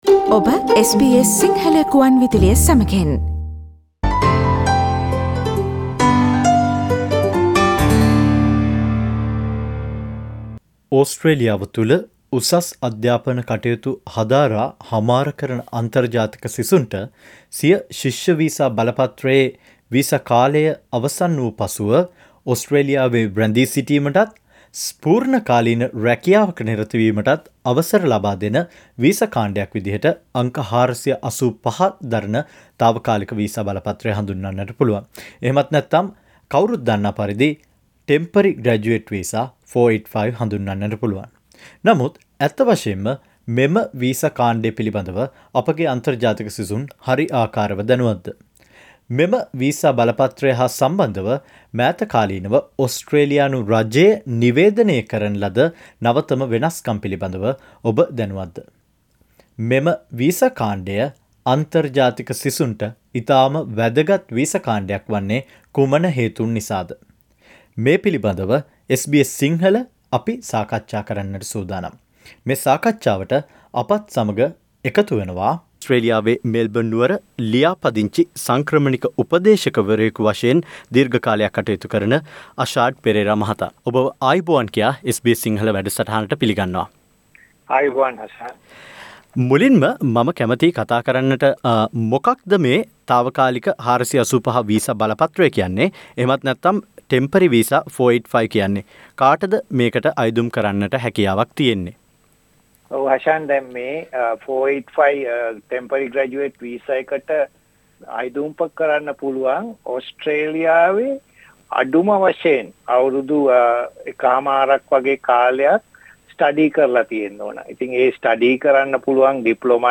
අංක 485 දරණ තාවකාලික වීසා බලපත්‍රය හා සම්බන්ද සුවිශේෂී තොරතුරු අඩංගු මෙම සාකච්ඡාවට සවන්දීමට ඉහත රූපය මත ඇති speaker සළකුණ click කරන්න.